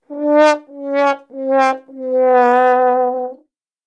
trombone.ogg